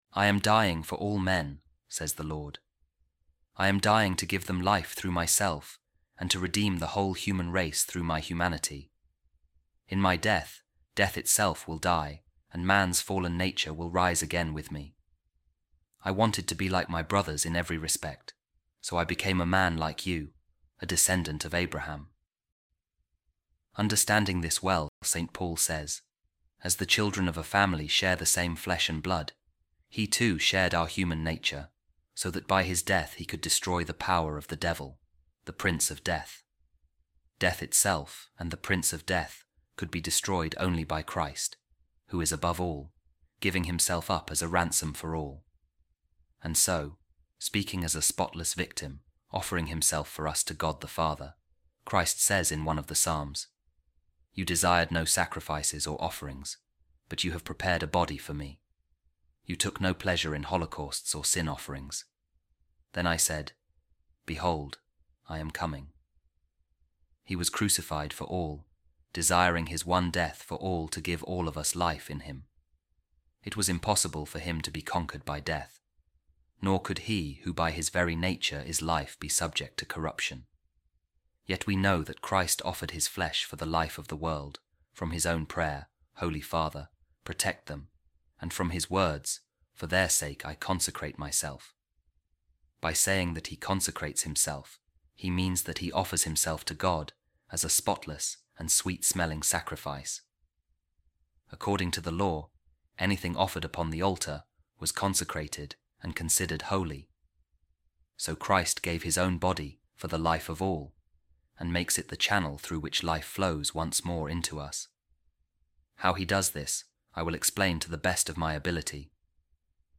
Office Of Readings | Eastertide Week 3, Saturday | A Reading From The Commentary Of Saint Cyril Of Alexandria On Saint John’s Gospel | Jesus Christ Gave His Body For The Life Of All